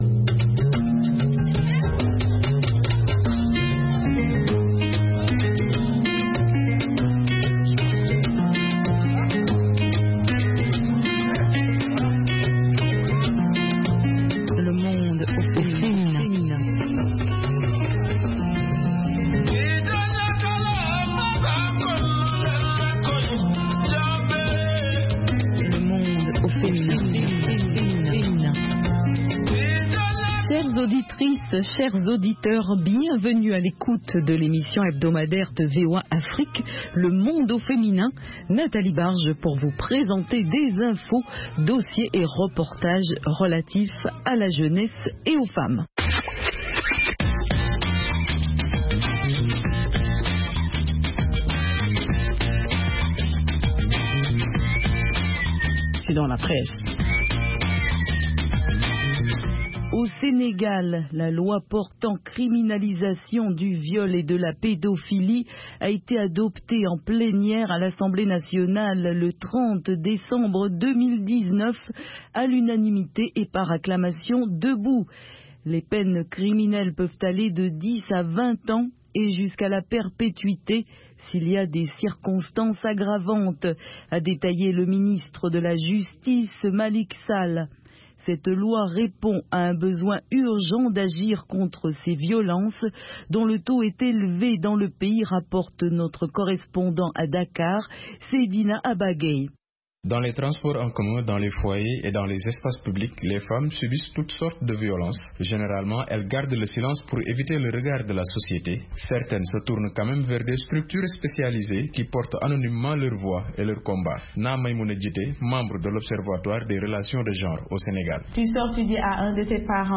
émission interactive